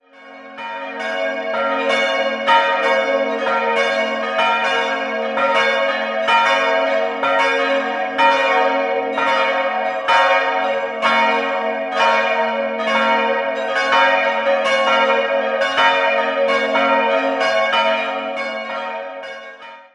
Außerdem ist von der Ausstattung noch eine spätgotische Madonna erwähnenswert. 4-stimmiges Geläut: b'-des''-es''-b'' Die kleinste Glocke wurde um das Jahr 1500 vermutlich in Nürnberg gegossen, die drei anderen goss im Jahr 1950 Rudolf Perner in Passau.